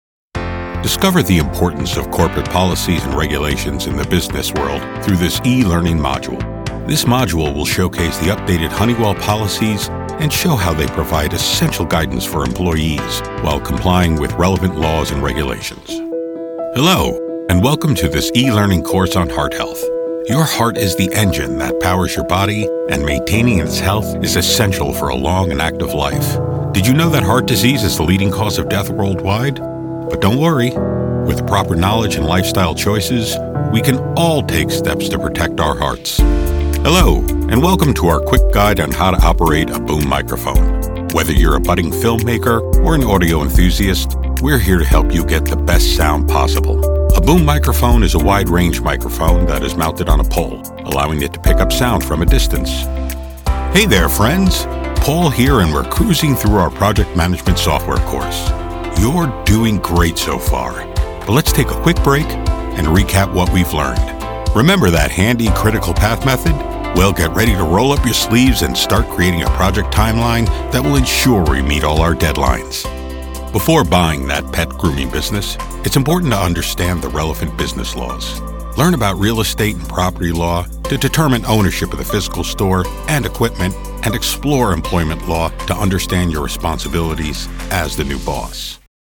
Warm, confident, and engaging.
Full Narration Demo – Versatile, Clear, and Engaging Delivery
Middle Aged
I record from a professional home studio with broadcast-quality sound and offer quick turnaround times.